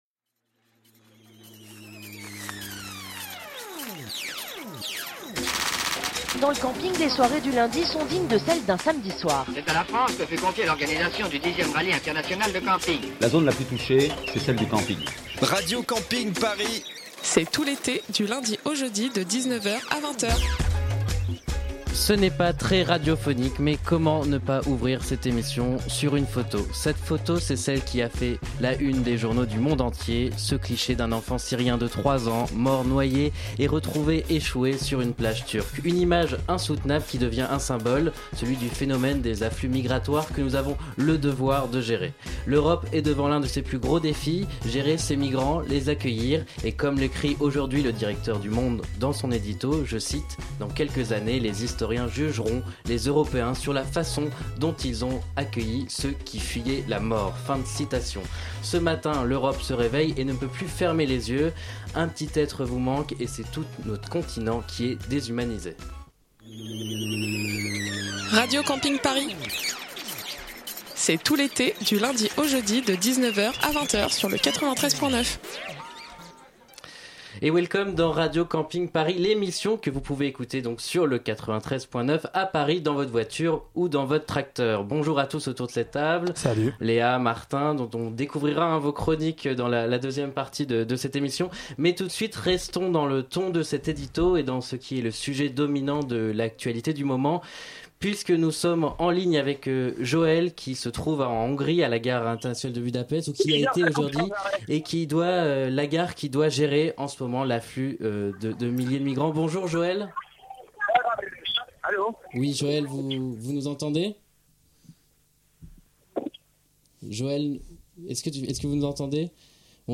________ (Au programme également : des chroniques, des blagues et un nouvel épisode de votre feuilleton de l'été Vol 939)